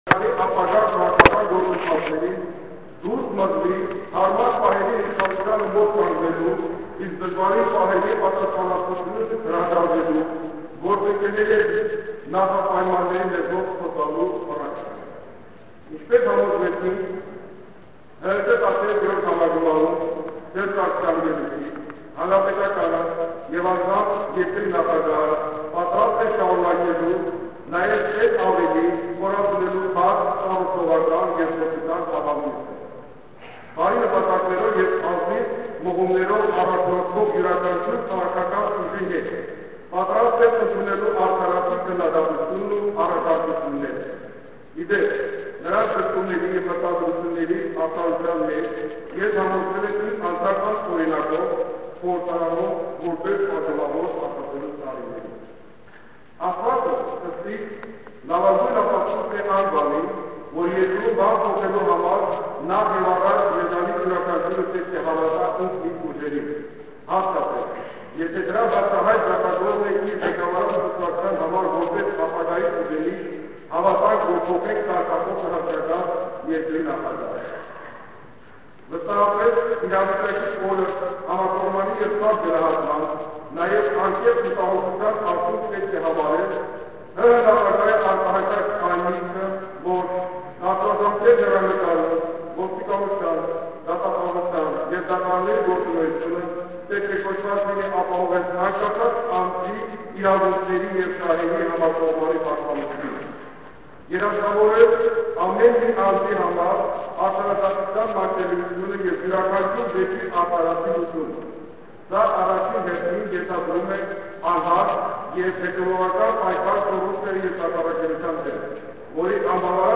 Այսօր Կառավարության նիստերի դահլիճում կայացած «Ժողովրդավարություն և Աշխատանք» կուսակցության համաժողովում ազդարարվեց, որ կուսակցությունը սատարելու է Հայաստանի Հանրապետական կուսակցությանը: